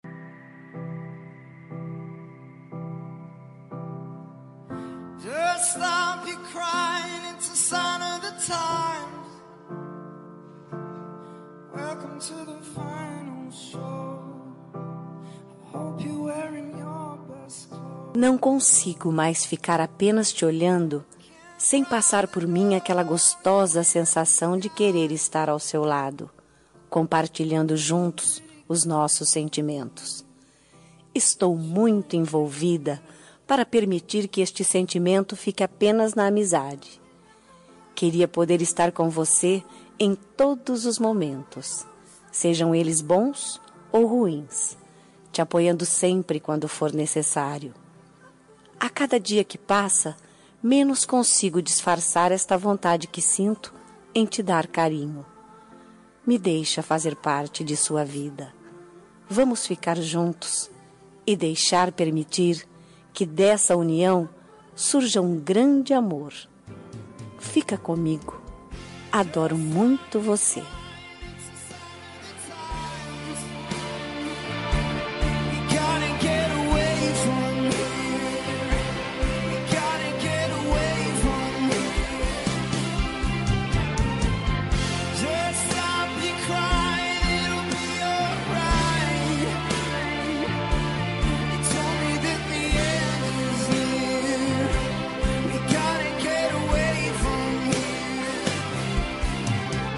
Telemensagem Paquera – Voz Feminina – Cód: 051584